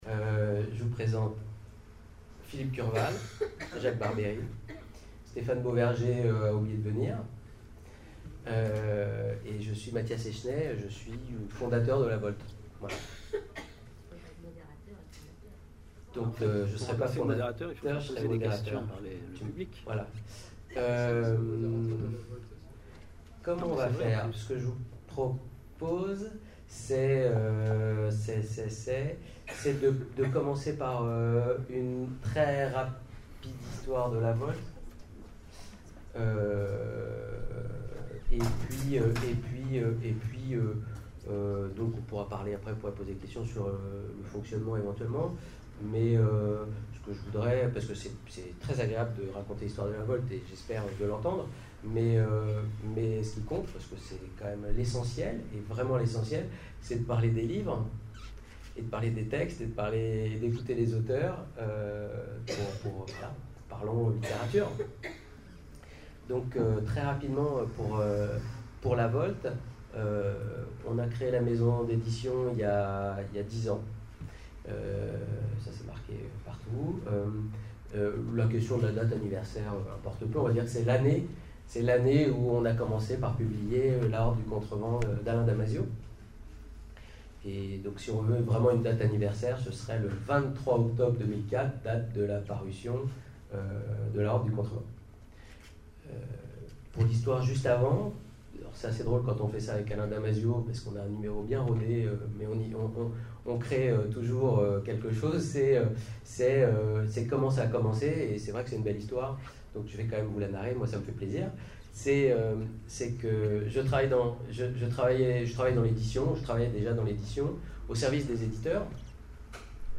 Sèvres 2014 : Conférence Les 10 ans de la Volte